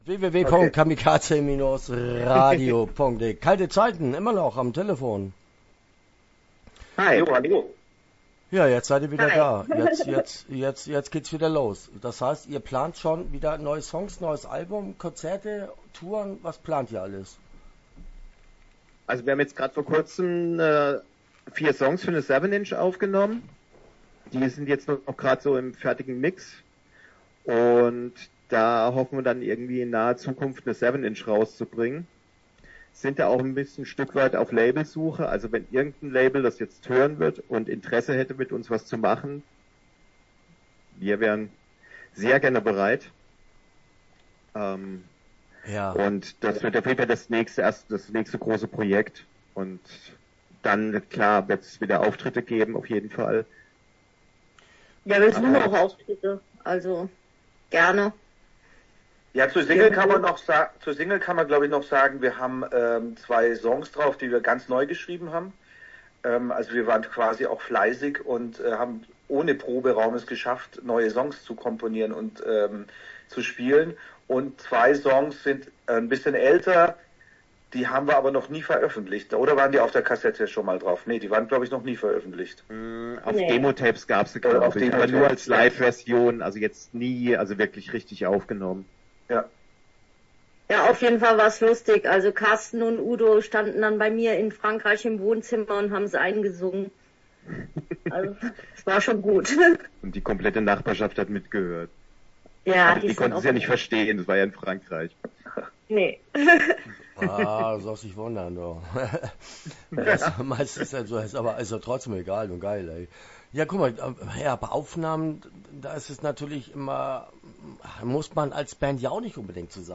Kalte Zeiten - Interview Teil 1 (10:52)